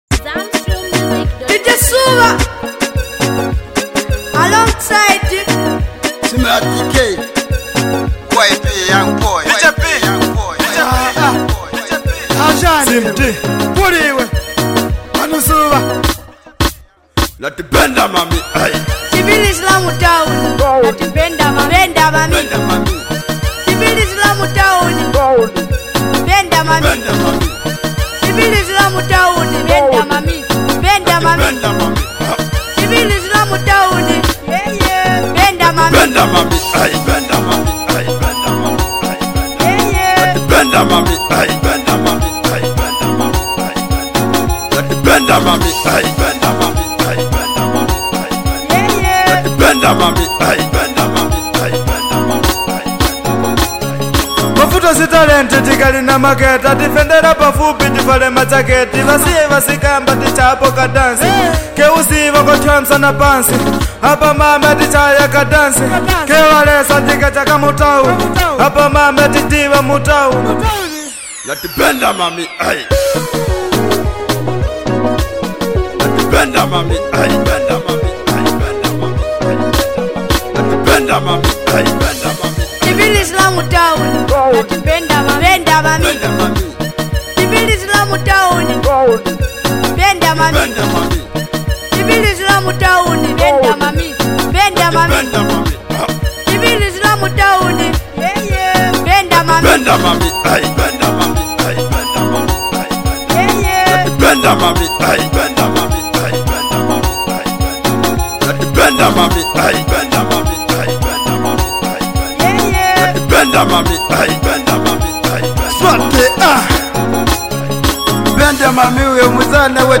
vibrate sound